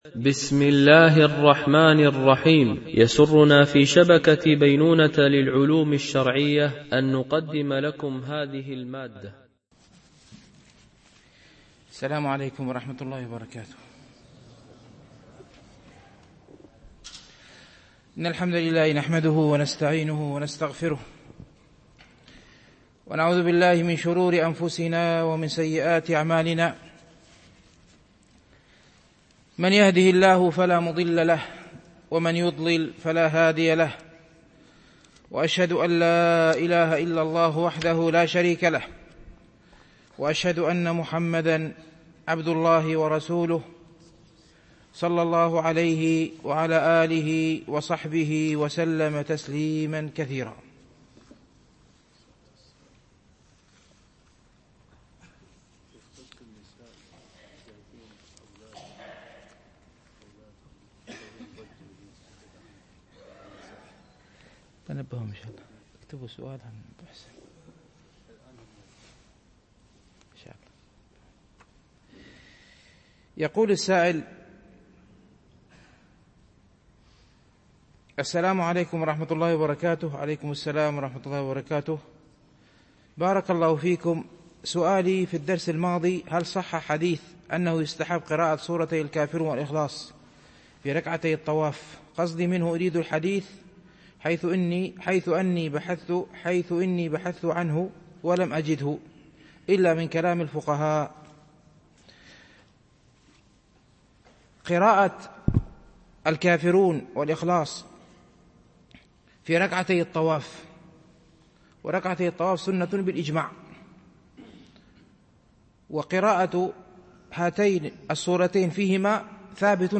شرح رياض الصالحين – الدرس 276 ( الحديث 1059 - 1060 )